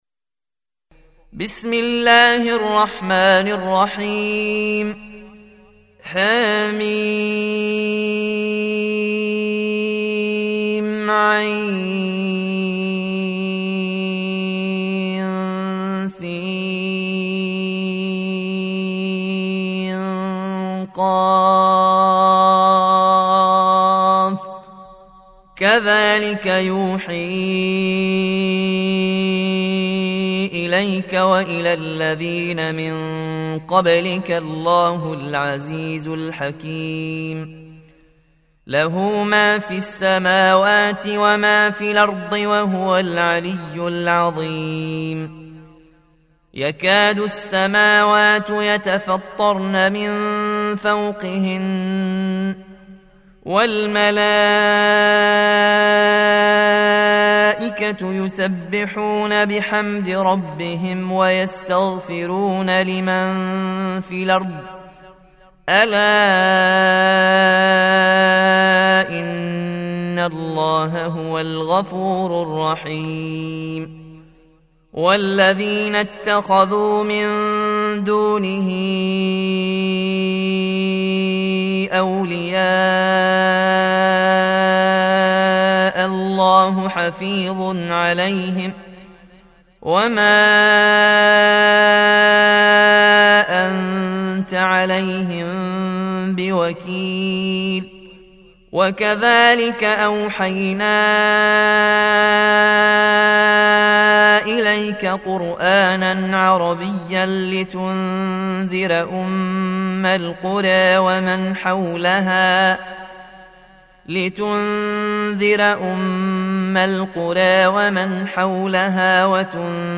42. Surah Ash-Sh�ra سورة الشورى Audio Quran Tarteel Recitation
Surah Sequence تتابع السورة Download Surah حمّل السورة Reciting Murattalah Audio for 42. Surah Ash-Sh�ra سورة الشورى N.B *Surah Includes Al-Basmalah Reciters Sequents تتابع التلاوات Reciters Repeats تكرار التلاوات